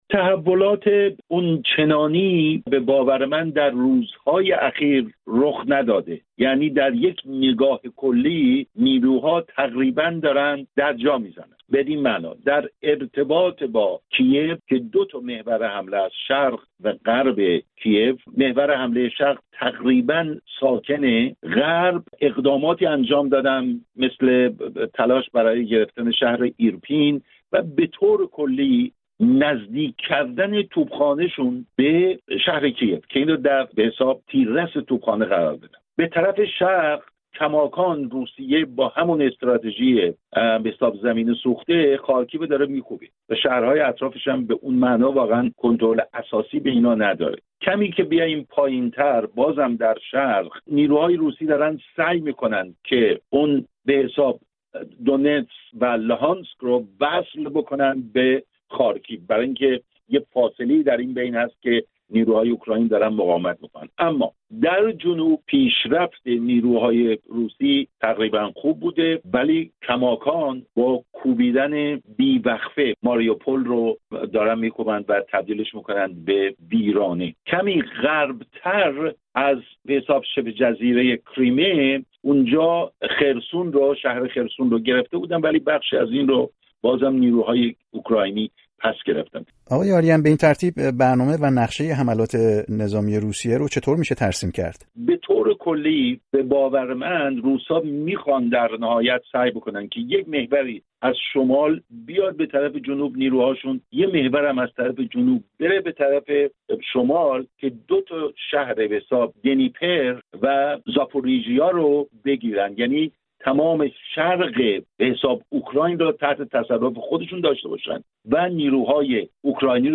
گفت‌وگو کرده است.